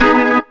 B3 GMIN 2.wav